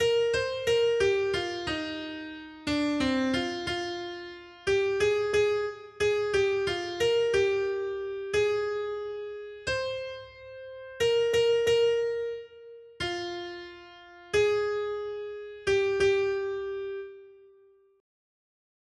Noty Štítky, zpěvníky ol440.pdf responsoriální žalm Žaltář (Olejník) 440 Skrýt akordy R: Hospodinova láska od věků pro ty, kdo se ho bojí. 1.